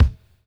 Index of /90_sSampleCDs/Drumdrops In Dub VOL-1/SINGLE HITS/DUB KICKS
DUBKICK-05.wav